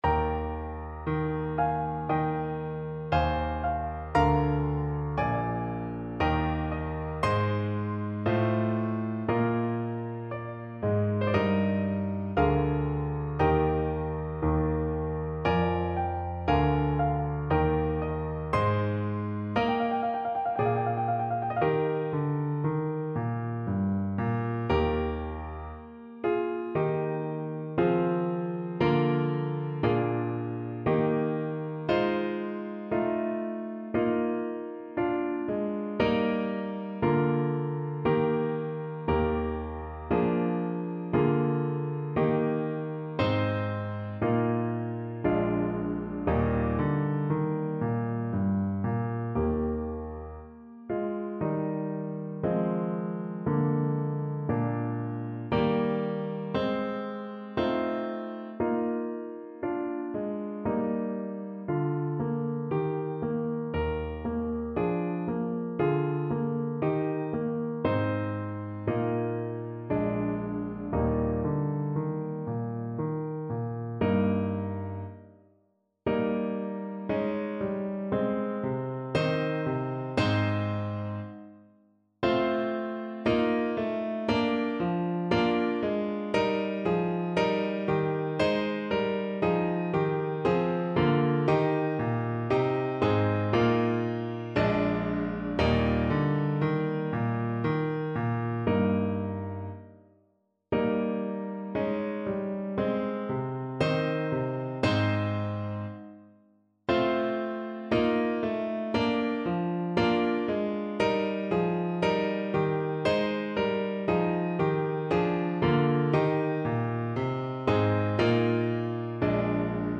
Trombone version
Andante =80
3/4 (View more 3/4 Music)
Classical (View more Classical Trombone Music)